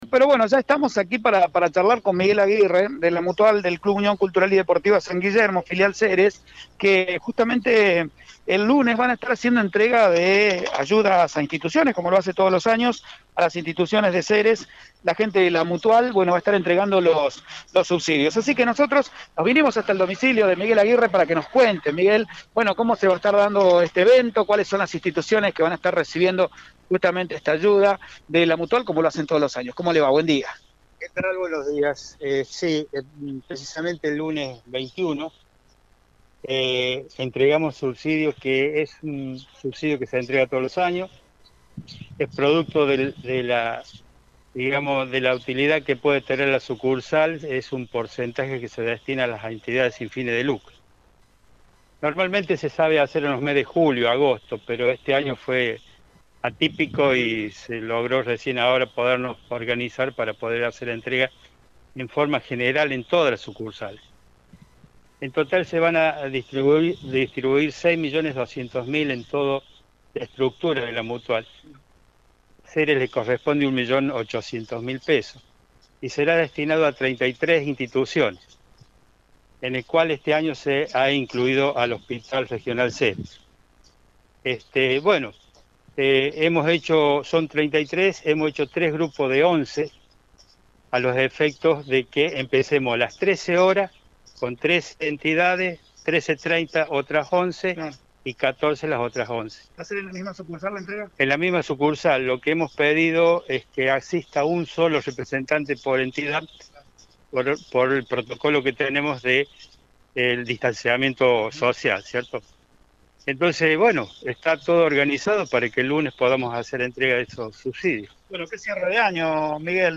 Radio Eme Ceres habló con